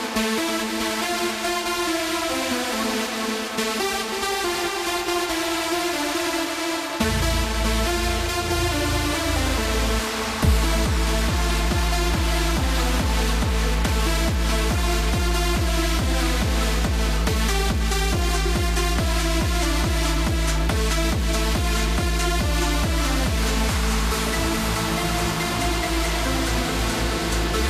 god i really underestimate some FM stations over here (1 attachments)